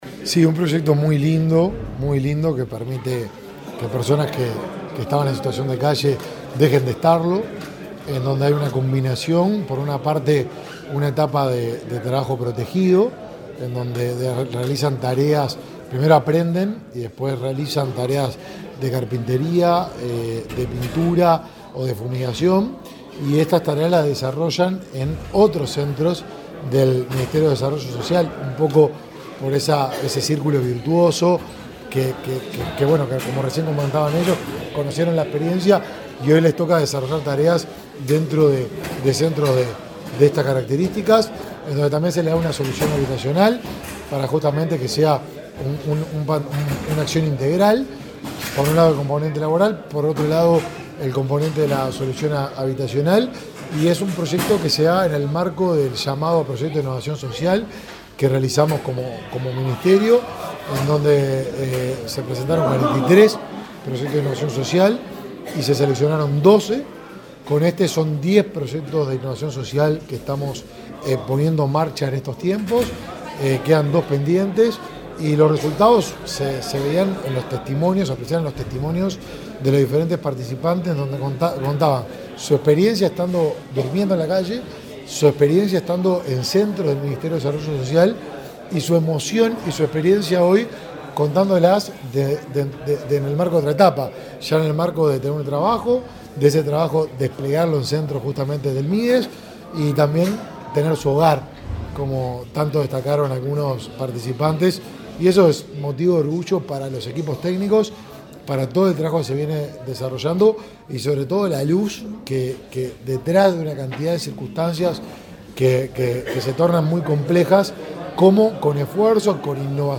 Entrevista al ministro de Desarrollo Social, Martín Lema